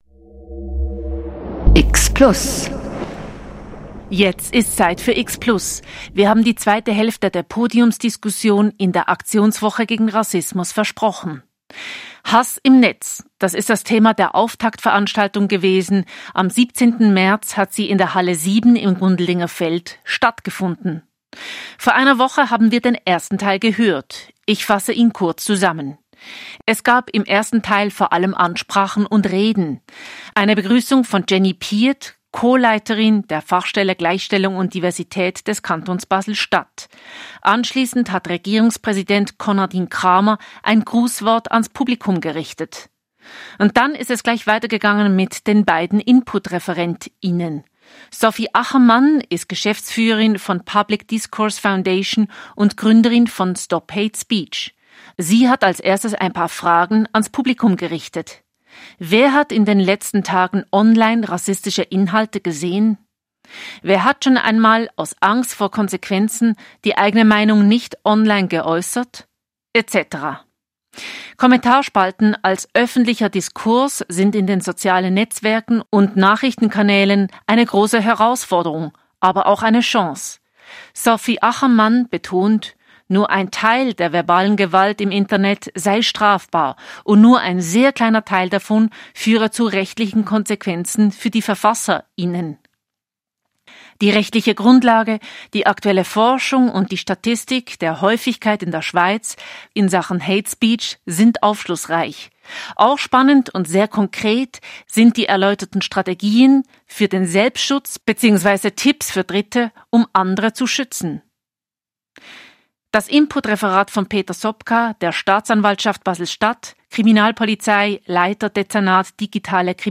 Podiumsdiskussion 2. Teil ~ Spezialthemen Podcast
Beschreibung vor 1 Jahr Die Aktionswoche gegen Rassismus 2025 ist mit der Podiumsveranstaltung offiziell eröffnet: Am Montag, 17. März um 17:00h waren fünf Gesprächspartner:innen in der Halle 7 in Basel miteinander im regen Austausch. Radio X bringt die anderthalb Stunden dauernde Diskussion als X-Plus-Sendungen in zwei Teilen.